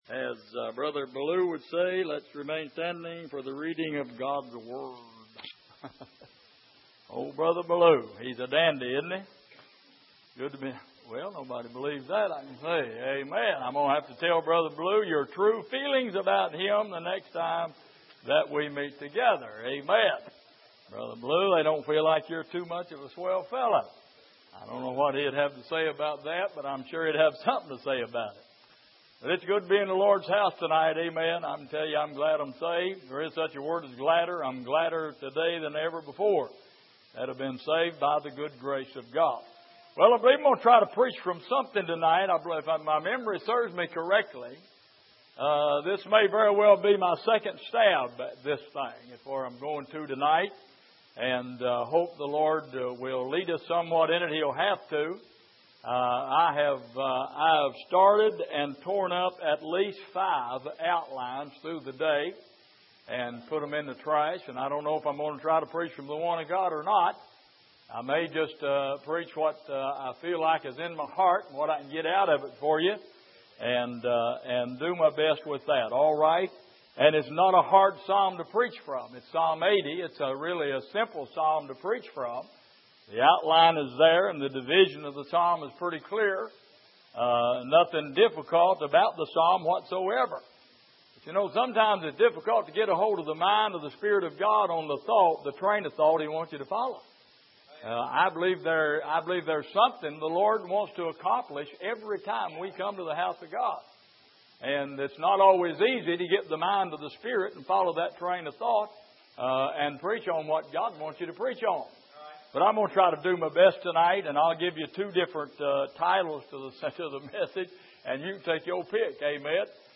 Exposition of the Psalms Passage: Psalm 80:1-19 Service: Midweek